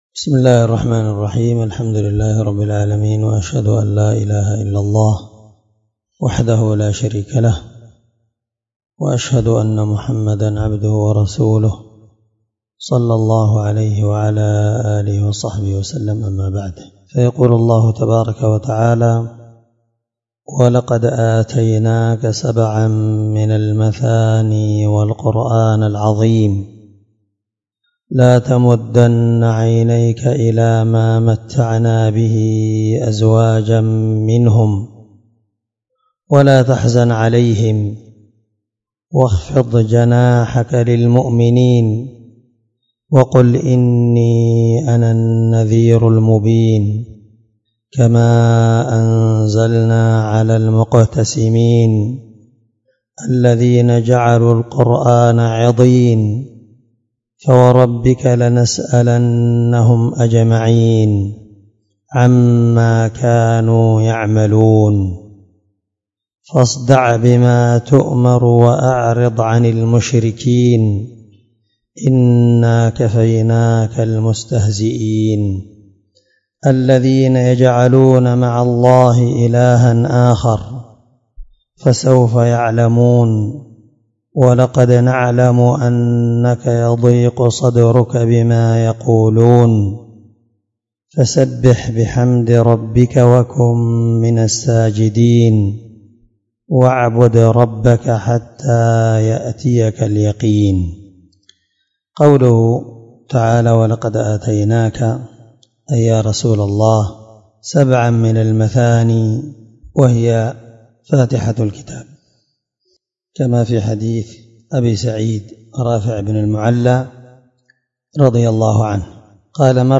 722الدرس13 تفسير آية (87-99) من سورة الحجر من تفسير القرآن الكريم مع قراءة لتفسير السعدي